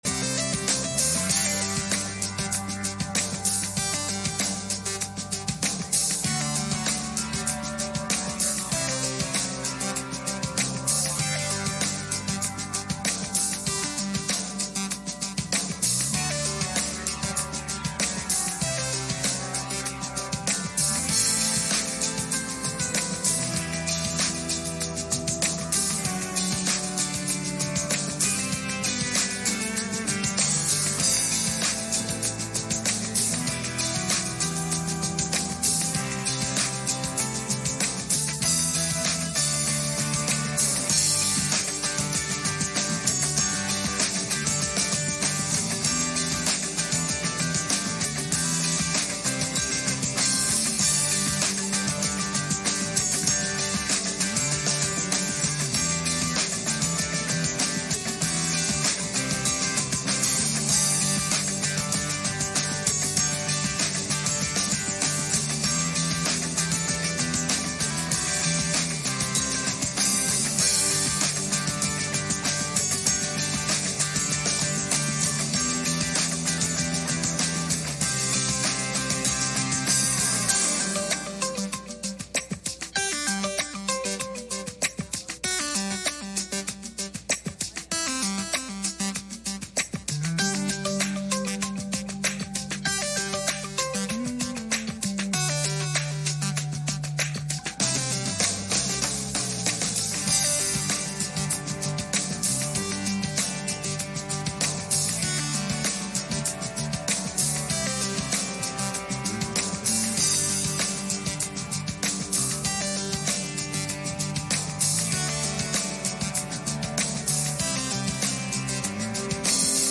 1 Samuel 25:2-3 Service Type: Sunday Evening « Abigail